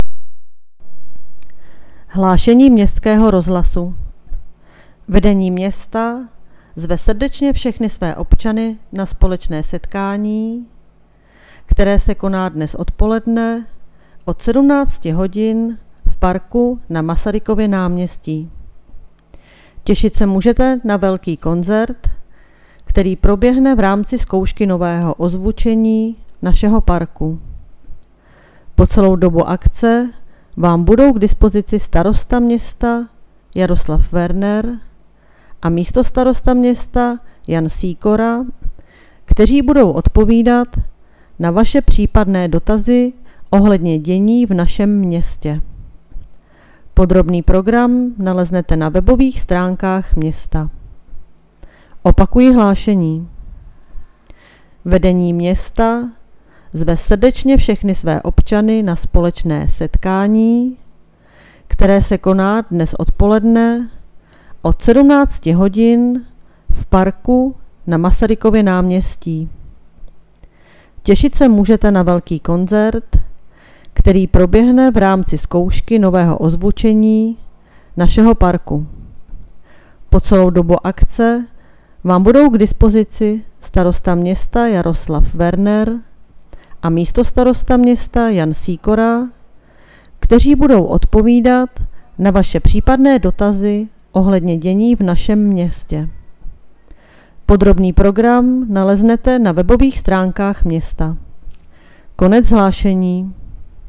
Hlášení městského rozhlasu 26.6.2020